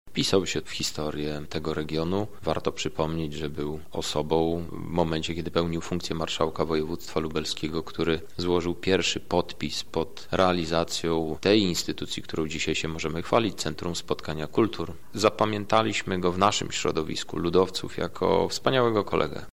Europoseł Krzysztof Hetman podczas porannych uroczystości w Lublinie mówił, że Edward Wojtas na trwałe zapisał się w pamięci ludowców i mieszkańców województwa.